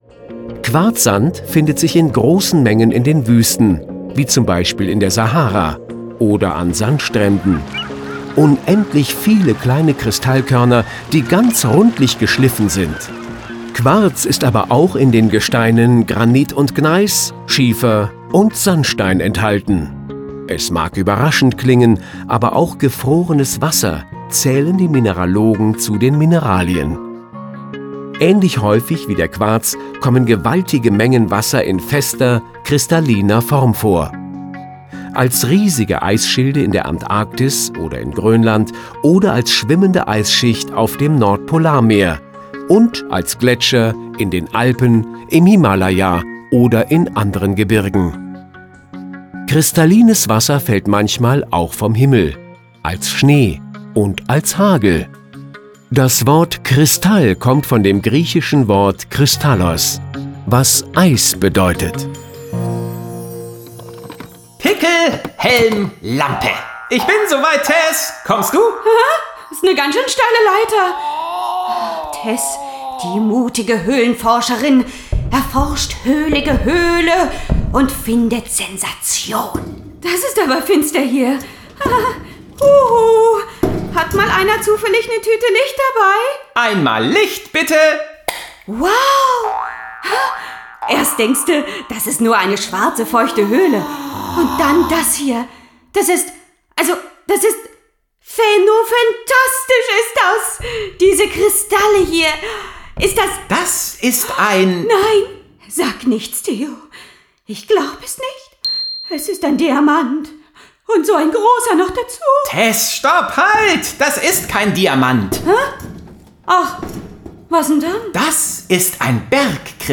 Schlagworte Boden • Diamant • Diamanten • Edelstein • Edelsteine • Erde • Erdöl • Hörbuch; Hörspiel für Kinder/Jugendliche • Hörspiel • Hörspiel für Kinder/Jugendliche • Hörspiel für Kinder/Jugendliche (Audio-CD) • Kiruna • Kohle • Kristall • Kristalle • Kristalle; Kindersachbuch/Jugendsachbuch • Kristalle; Kindersachbuch/Jugendsachbuch (Audio-CDs) • Mineral • Öl • Schätze • schwarzes_gold • schwarzes Gold • Vulkan • Vulkane